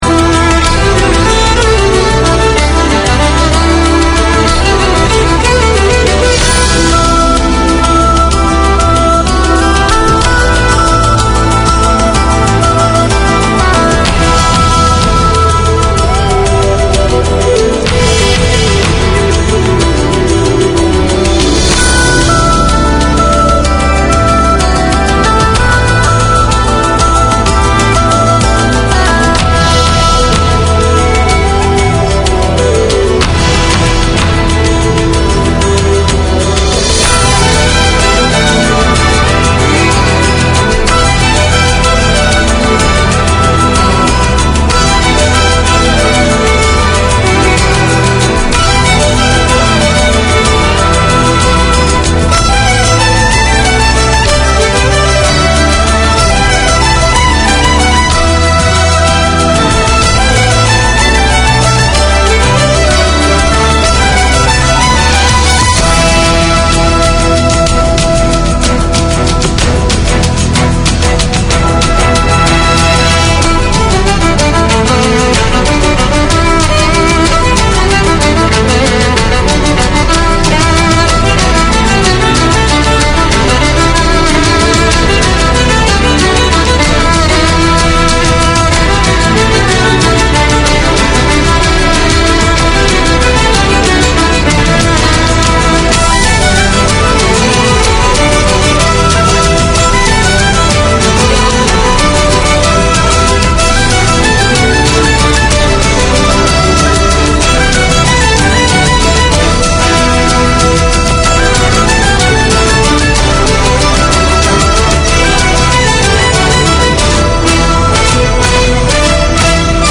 Catering to a wide community of Arabic, Syriac, Chaldean and Kurdish speakers, Voice of Mesopotamia presents an engaging and entertaining hour of radio. Tune in for interviews with both local guests and speakers abroad, a youth-led segment and music from across Mesopotamia.